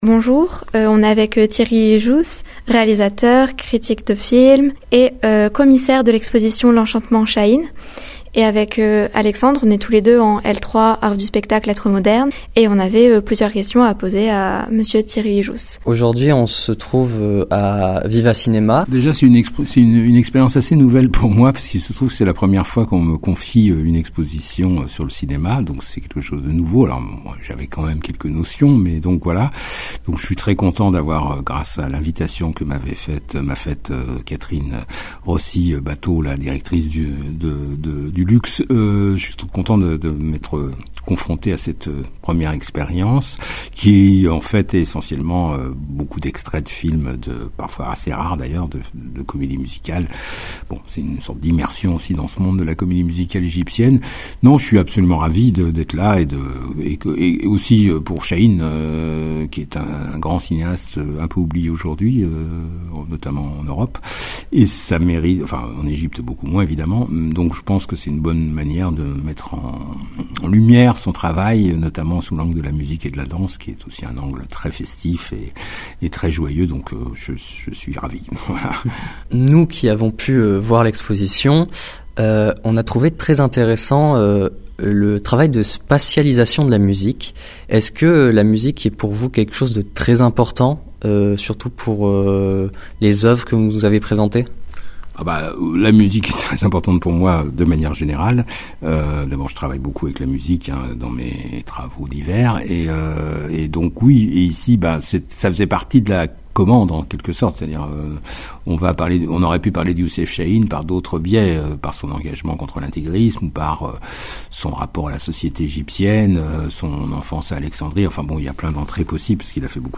Cette année, la Radio du Cinéma a choisi de passer exceptionnellement le micro aux étudiants en L3 Arts du spectacle Lettres Modernes de Valence.
Une première interview, menée micro en main, dans un contexte professionnel mais bienveillant, où la curiosité étudiante trouve toute sa place.